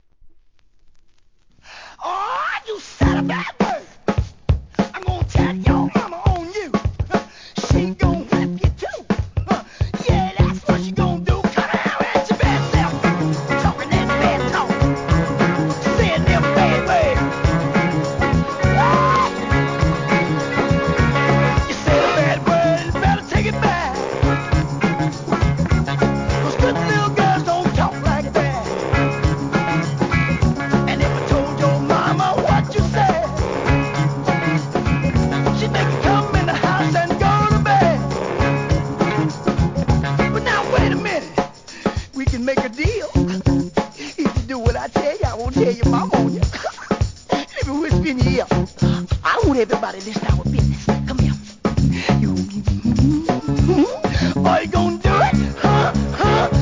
¥ 1,100 税込 関連カテゴリ SOUL/FUNK/etc...
フロアKILLER FUNK!!